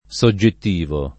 SoJJett&vo] agg. — nell’uso filos., giur., med., anche subiettivo [SubLett&vo] o subbiettivo [SubbLett&vo] — la stessa alternanza nei der. ‑are, ‑azione, ‑ismo, ‑ista, ‑istico, ‑ità